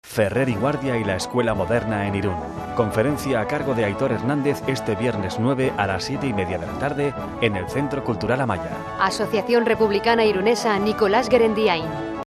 Cuñas en "Radio Irun" anunciando la presentación del libro